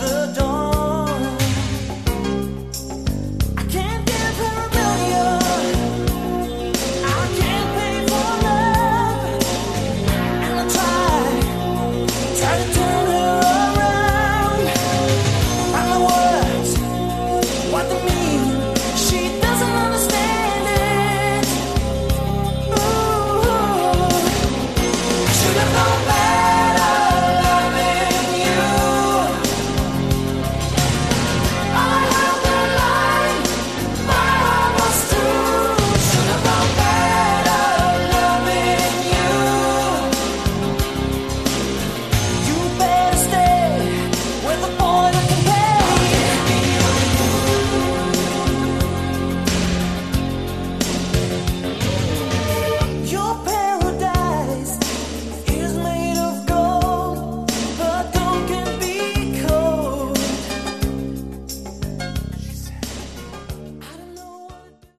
Category: AOR
vocals, guitars
keyboards
bass
drums